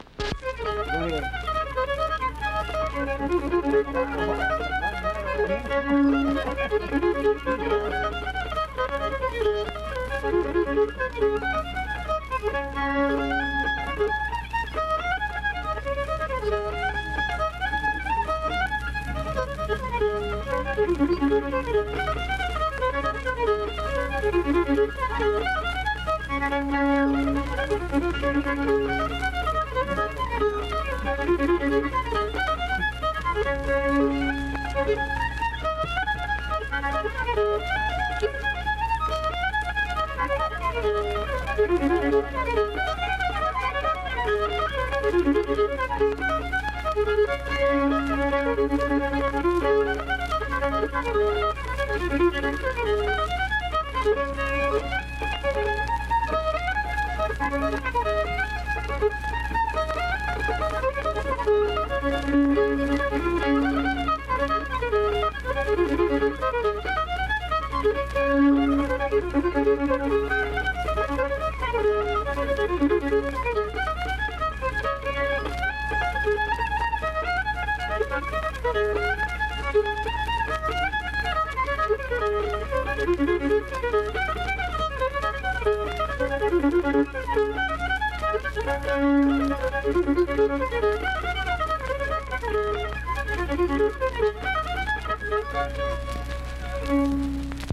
Instrumental fiddle performance.
Instrumental Music
Fiddle
Vienna (W. Va.), Wood County (W. Va.)